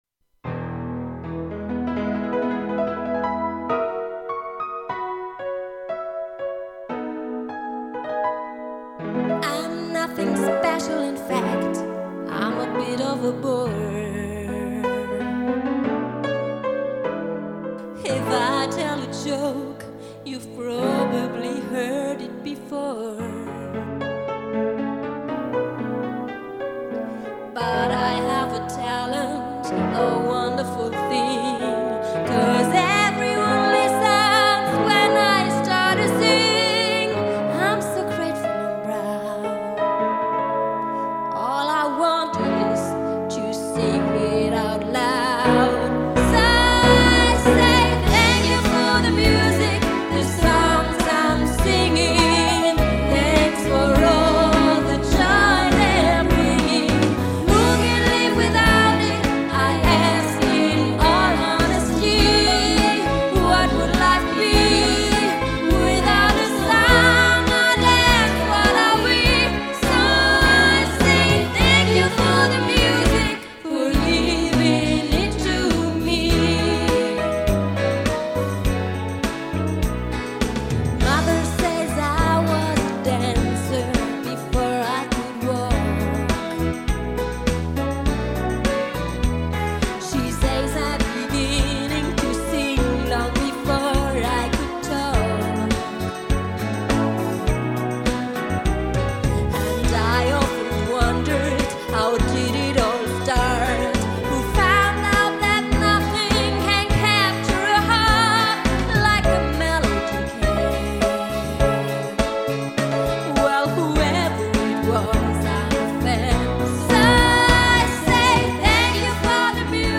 Wir stehen für eine kleine Besetzung mit großem Sound!